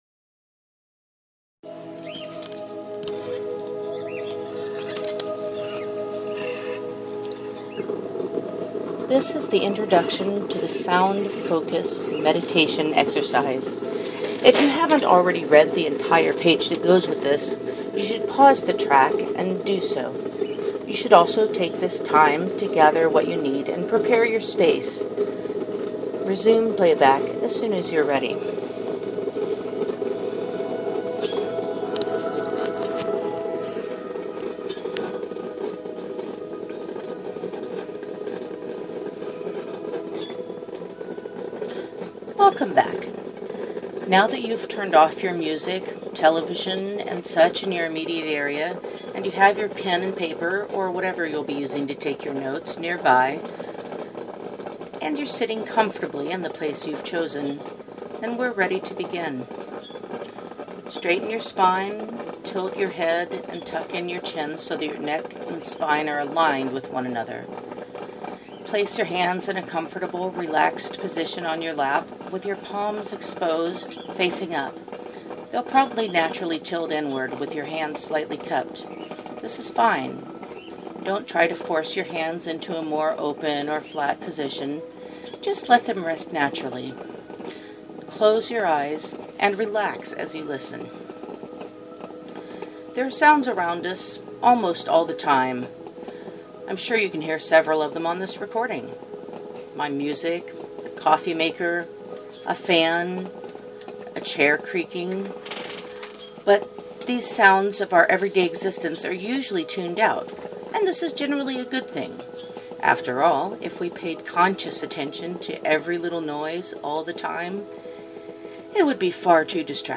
GuidedMeditation-soundfocus.mp3